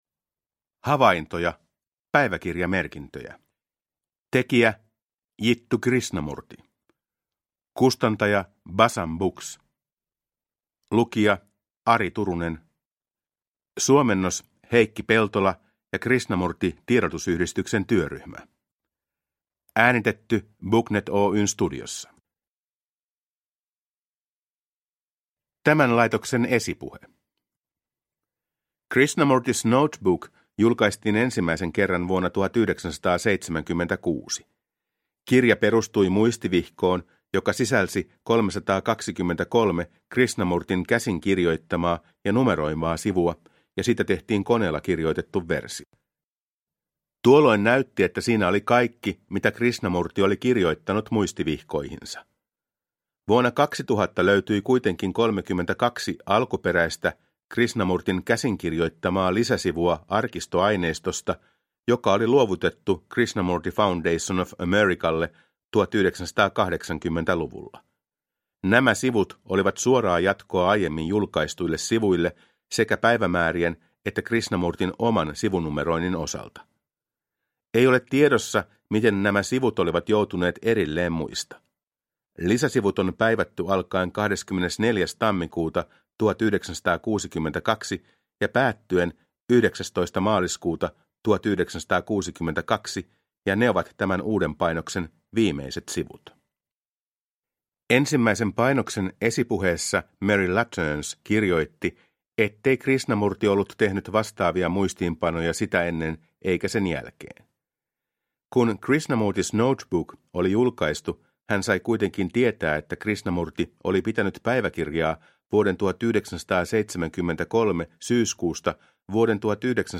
Havaintoja – Ljudbok